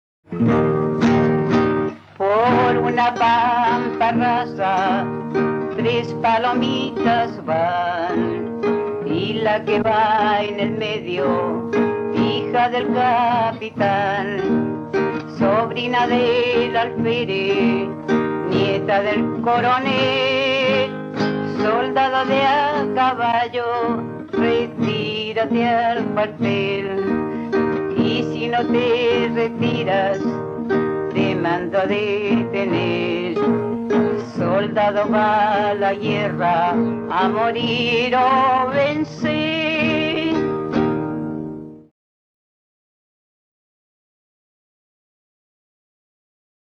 Romance en forma de habanera que trata el tema tradicional de "la hija del capitán".
Música tradicional
Folklore
Habanera